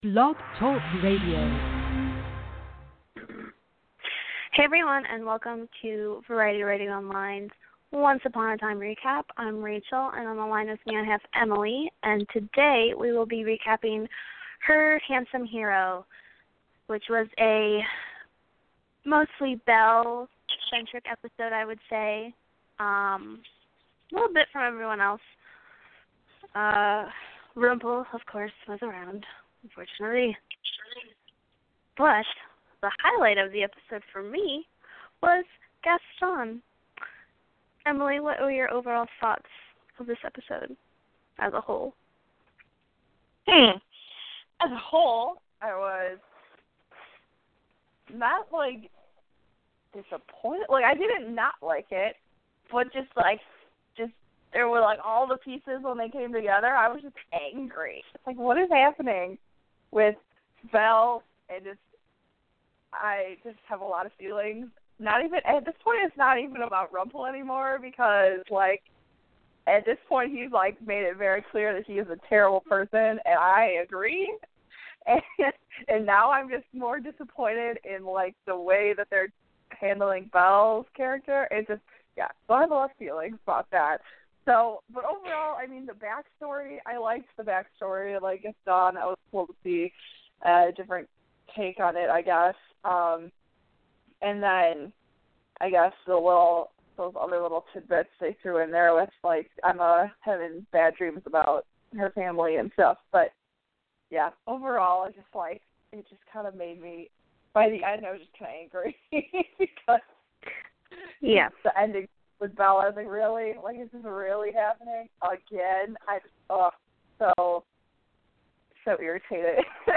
Join our Once Upon a Time hosts every Monday as they discuss the highlights of the latest episode of Once Upon a Time - E-mail your thoughts on the episode and the host will read them on air.